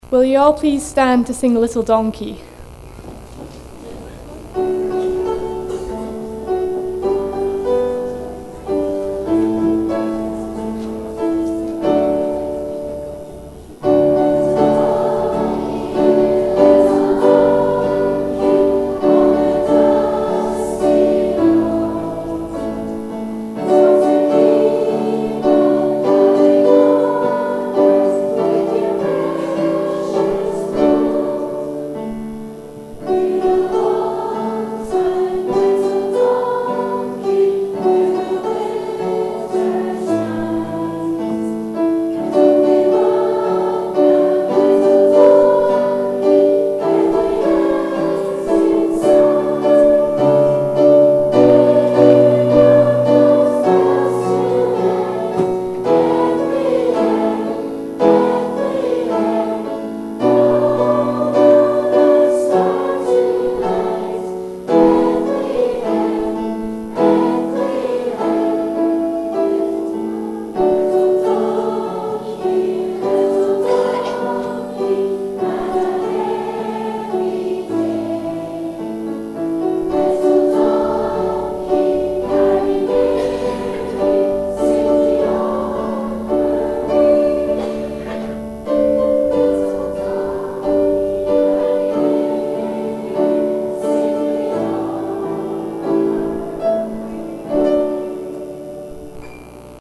Girlguiding Christmas - 5 December 2018
Click on the links to hear the carol singing and service
On Wednesday 5th of December St. Mungo's played host to Penicuik and Midlothian Girlguiding, Rangers, Brownies, Trefoil and Rainbows girls, parents and friends, for their annual Christmas Service.